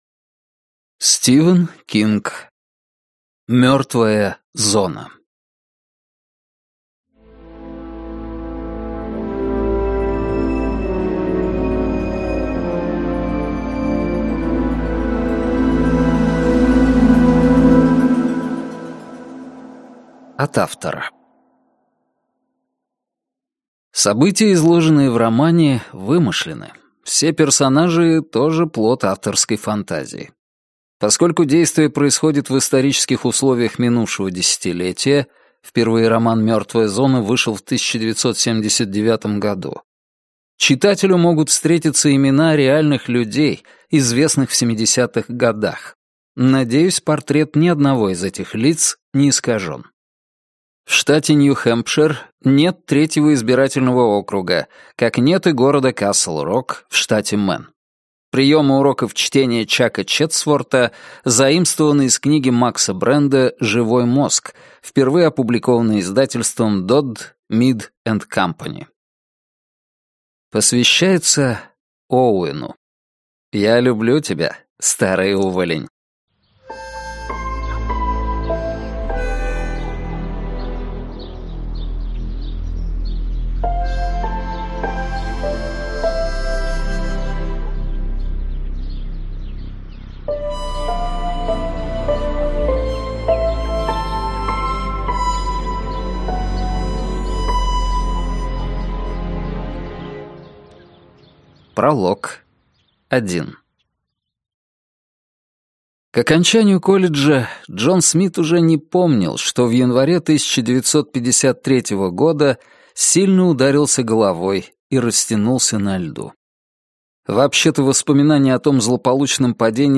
Аудиокнига Мёртвая зона - купить, скачать и слушать онлайн | КнигоПоиск